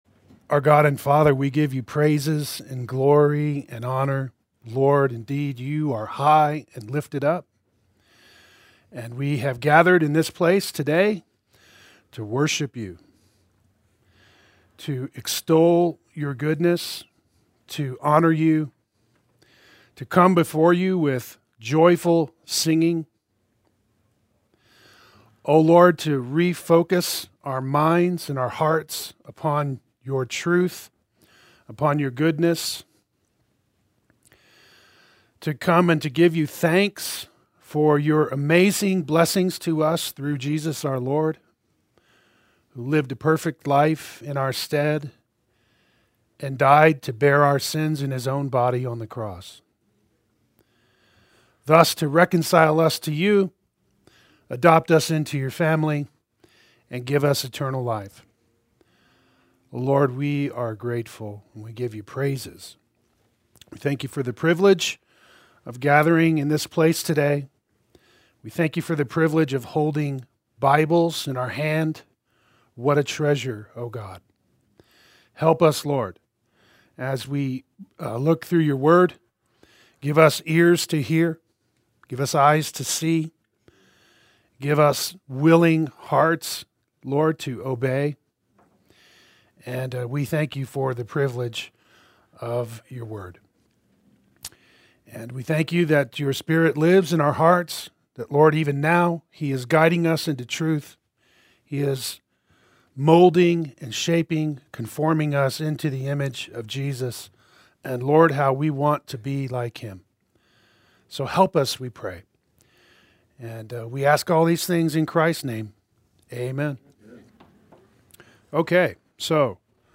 Play Sermon Get HCF Teaching Automatically.
Equipping and Building Up the Body Adult Sunday School